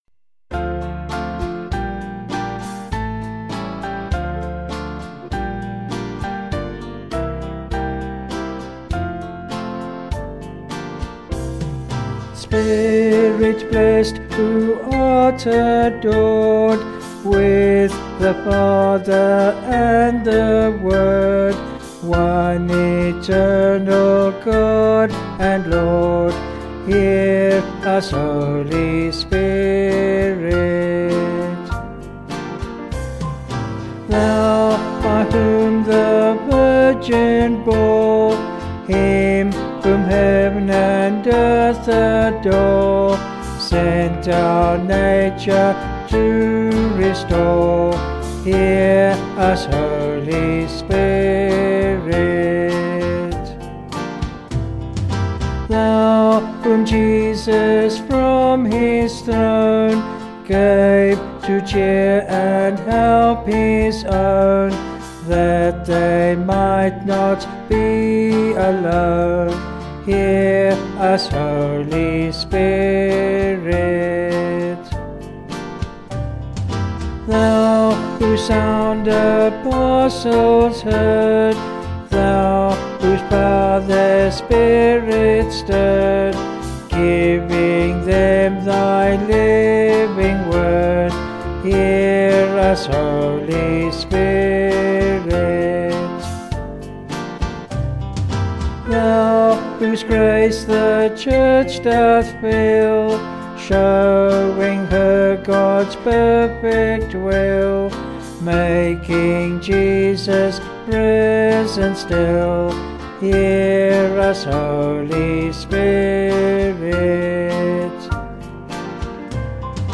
Vocals and Organ   265.2kb Sung Lyrics 3.4mb